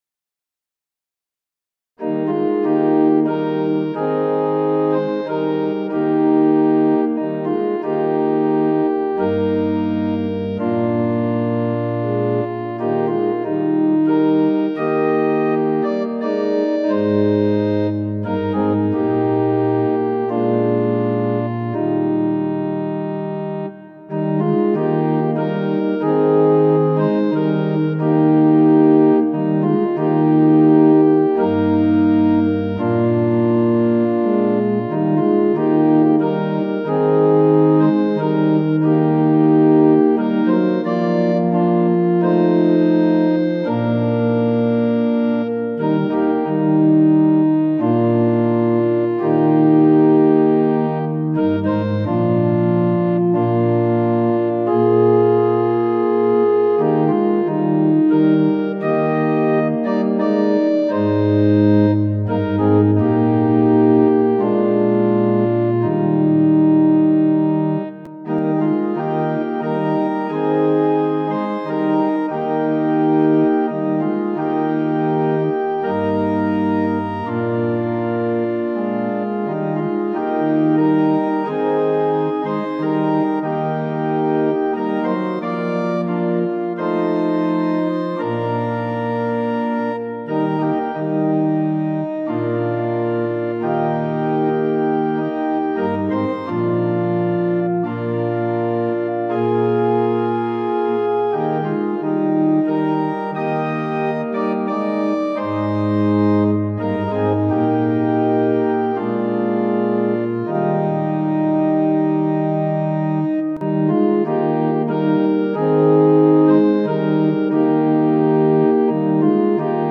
Tonality = Es Pitch = 440 Temperament = Equal